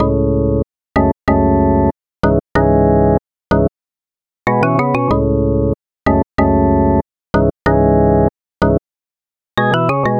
Ridin_ Dubs - Low Organ.wav